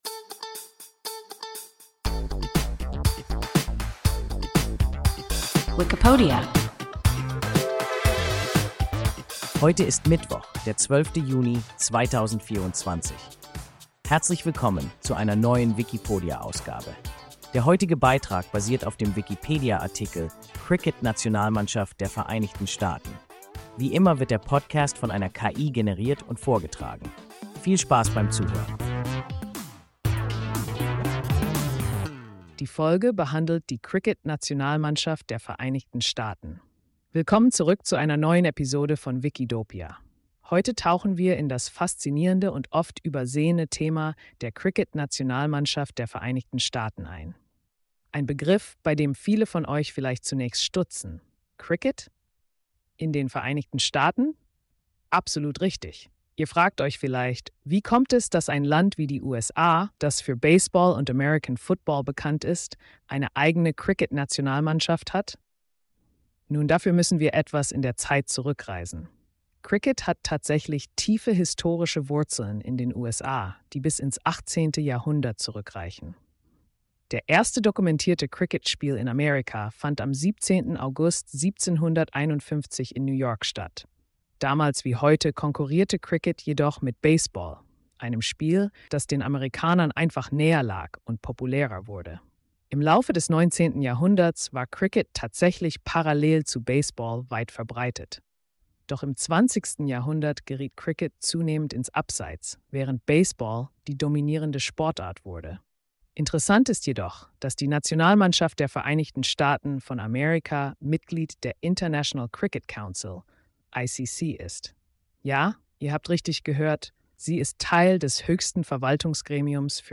Cricket-Nationalmannschaft der Vereinigten Staaten – WIKIPODIA – ein KI Podcast